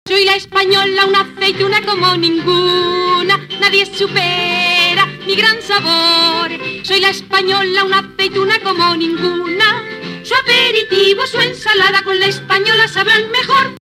Publicitat cantada
Gènere radiofònic Publicitat